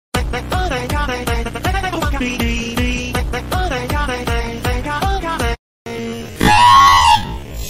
slowed down